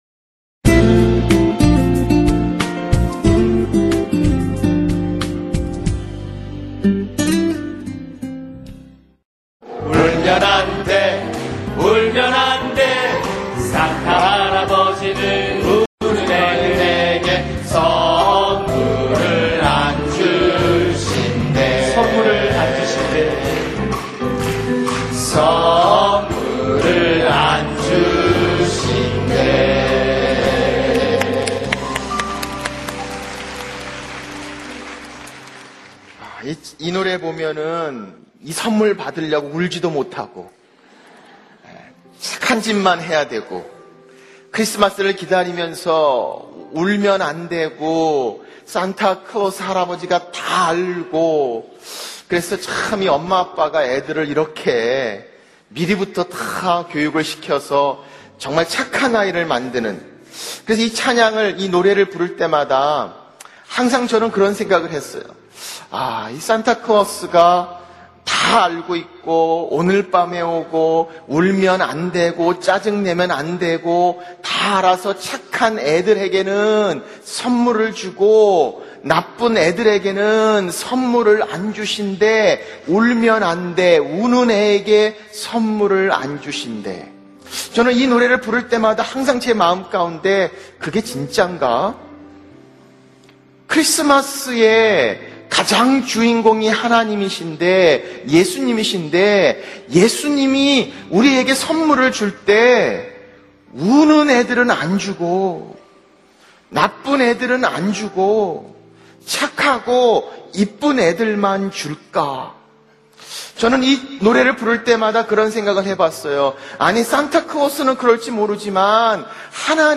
설교 | 크리스마스 진짜 선물!